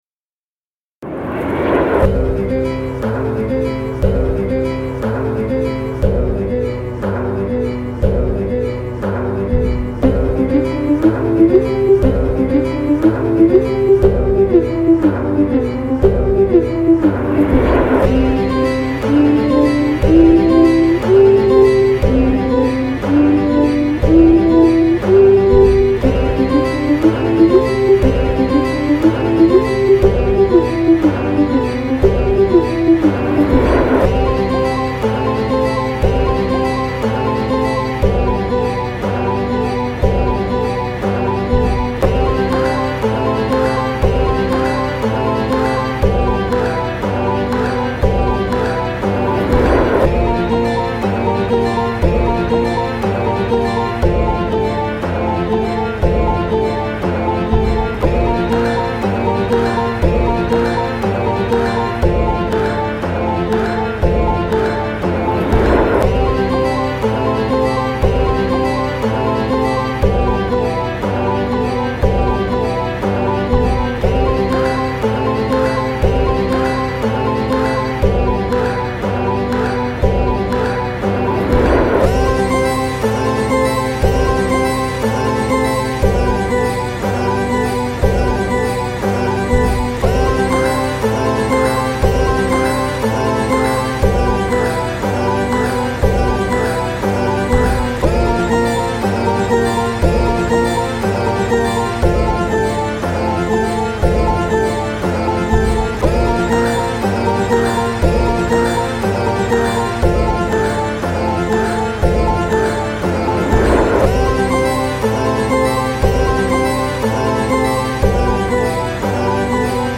The cuts were pitched up or down, EQed and rearranged.